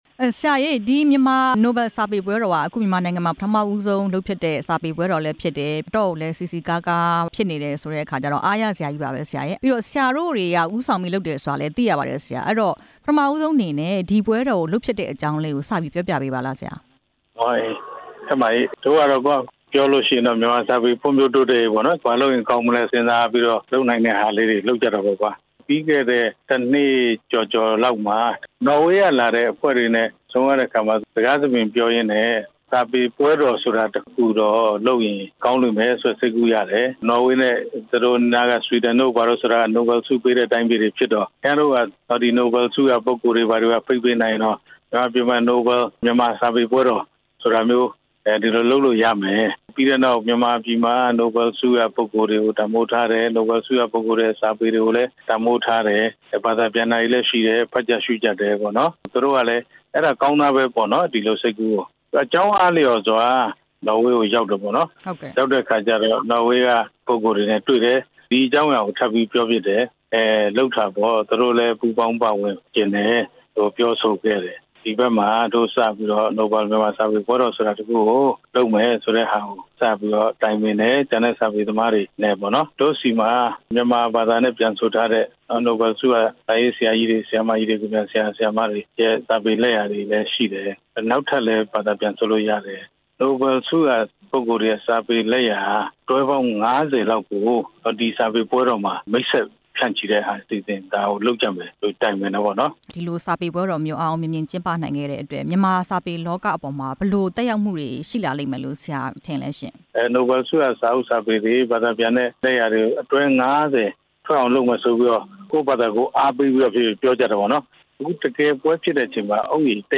မြန်မာနိုဘယ်စာပေပွဲတော် စာရေးဆရာဖေမြင့်ကို မေးမြန်းချက်
ဆရာဖေမြင့်နဲ့ မေးမြန်းချက်အပြည့်အစုံ